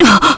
Pavel-Vox_Damage_kr_01.wav